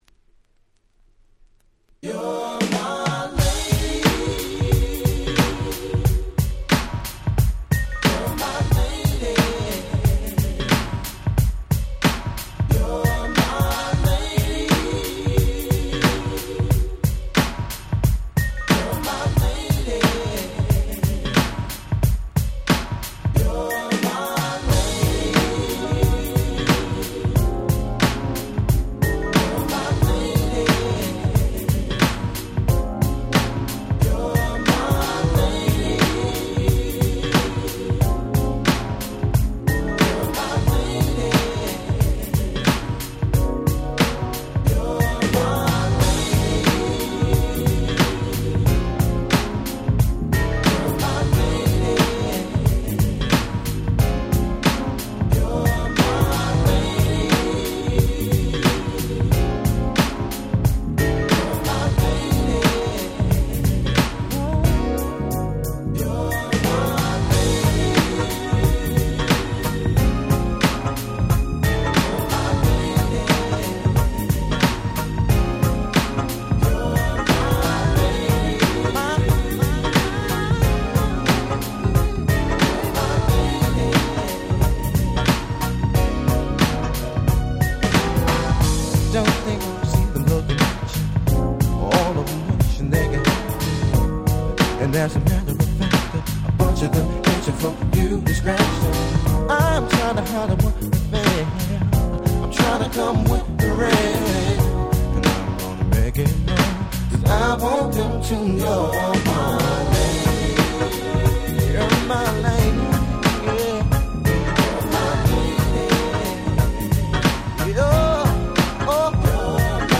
96’ R&B Super Classics !!